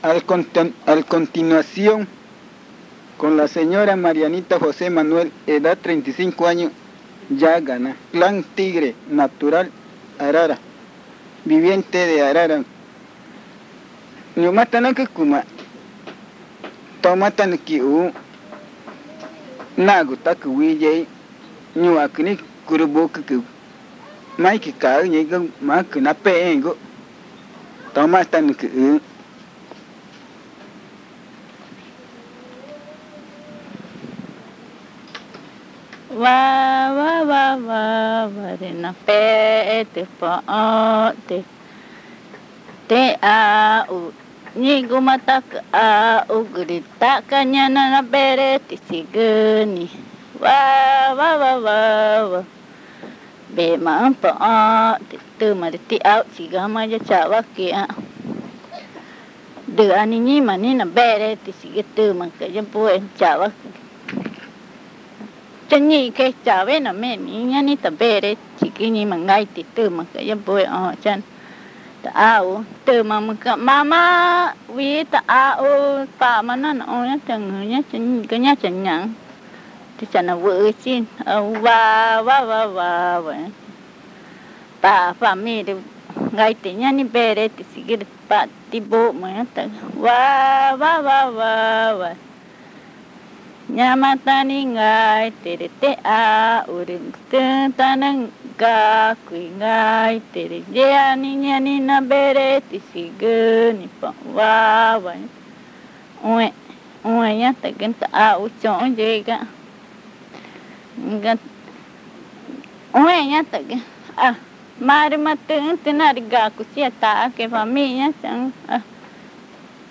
Lullaby 5
Comunidad Indígena Arara
Arrullo cantado
Lullaby sung
in the Indigenous Community of Arara (Amazonas, Colombia)